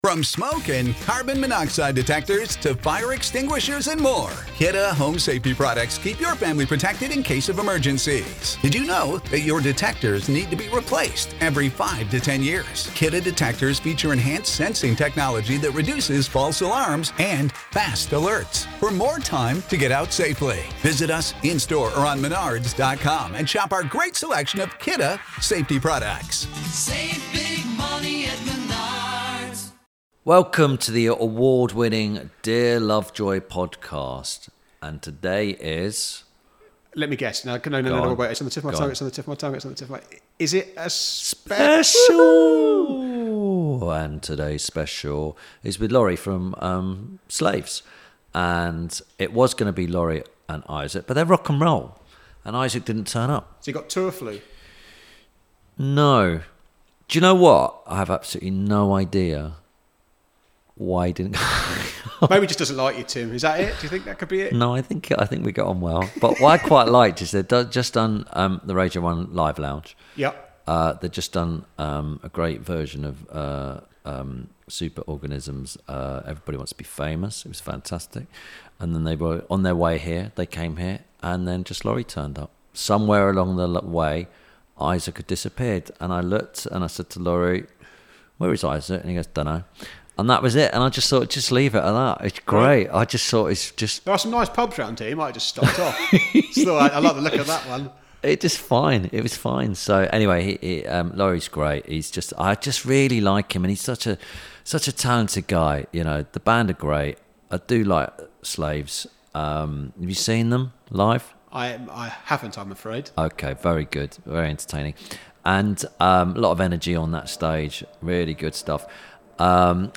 – INTERVIEW SPECIAL
This week Tim Lovejoy talks to Slaves vocalist and guitarist Laurie Vincent. Tim and Laurie discuss the triple A's - apple cider vinegar, auto-tuning and album covers.